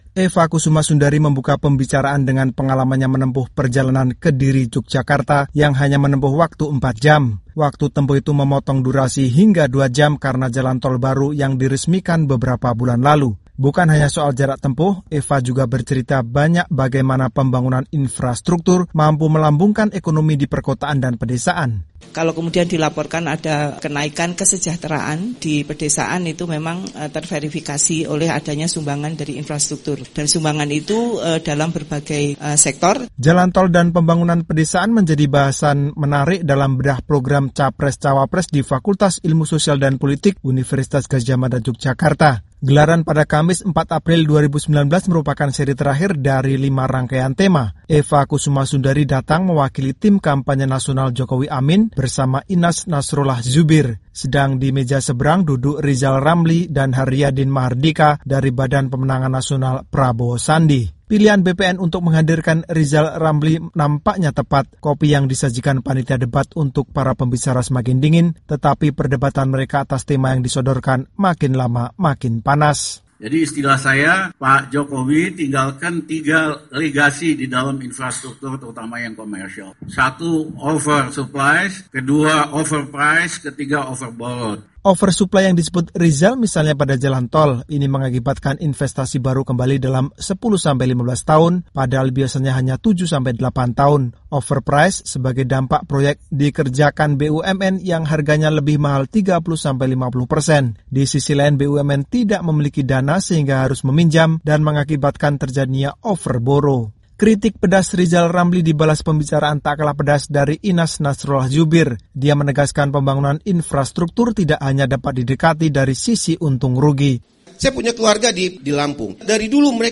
Bedah Program Capres-Cawapres kembali digelar di Universitas Gadjah Mada, Yogyakarta. Perbincangan berjalan seru karena membahas sejumlah tema menarik, seperti infrastruktur dan dana desa.